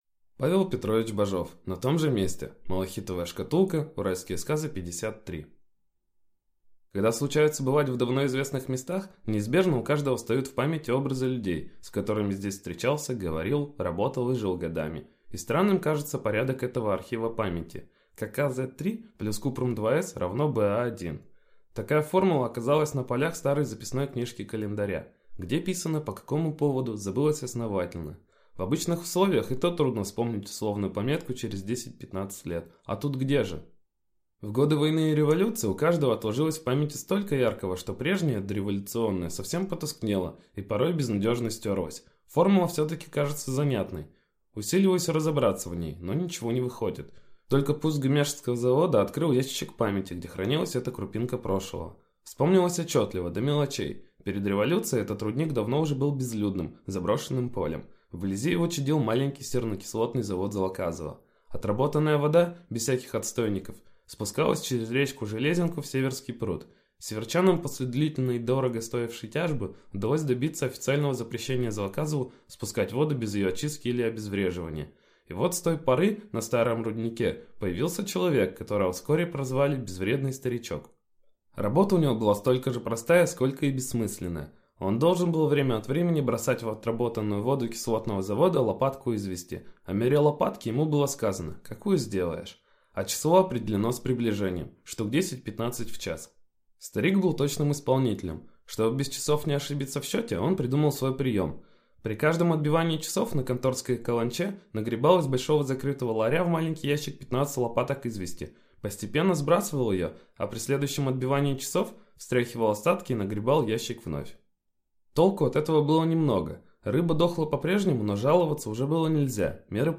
Аудиокнига На том же месте | Библиотека аудиокниг